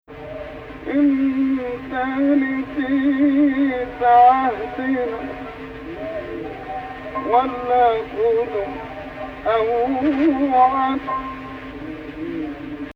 Type: 2nd Saba 6/8 => 2nd Rast 5/8